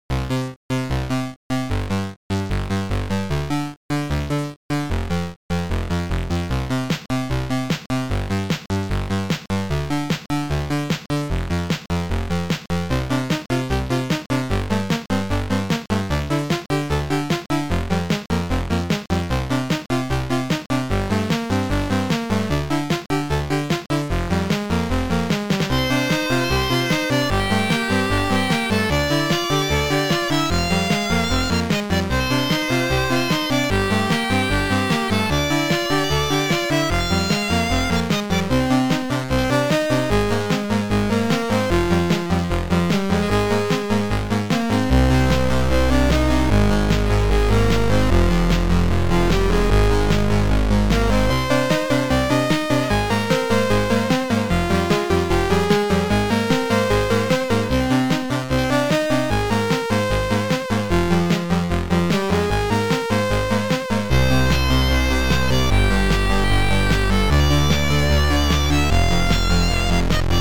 SidMon II Module
2 channels